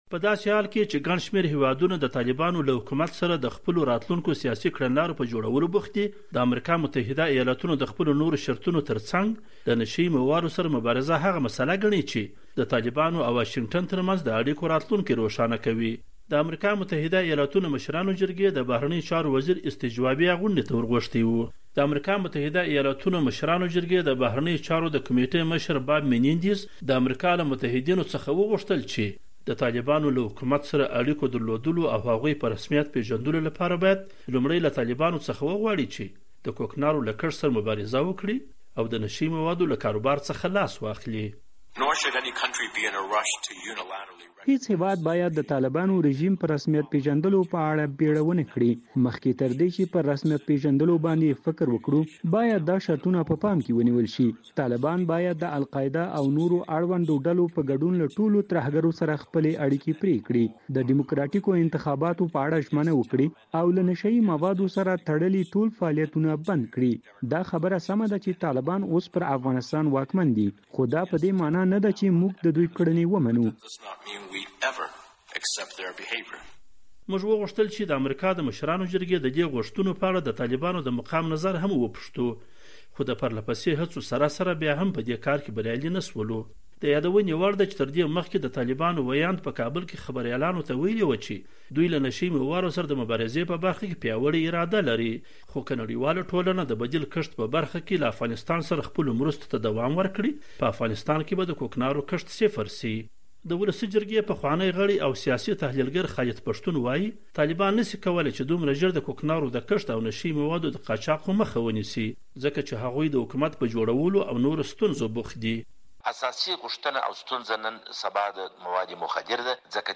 د زهرو کاروان- راپور